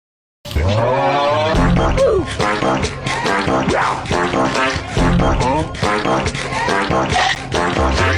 Sound Effects
Goofy Ahh Fart Music